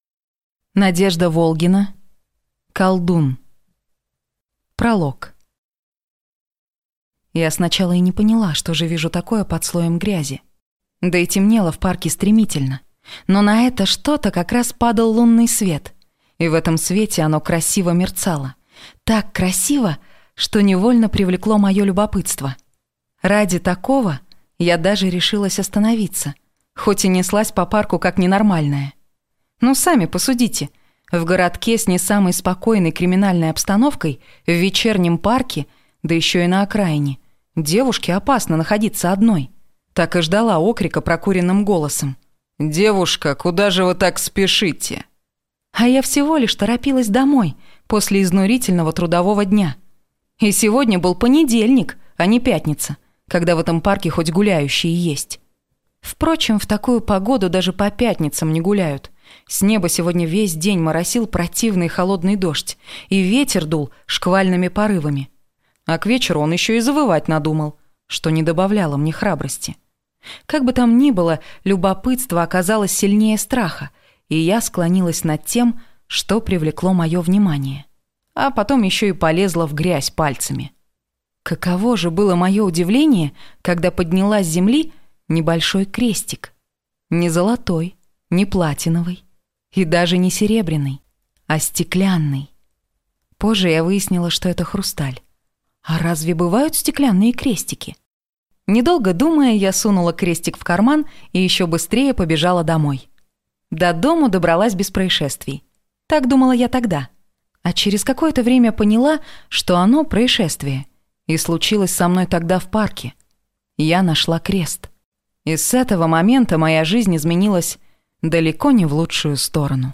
Аудиокнига Колдун | Библиотека аудиокниг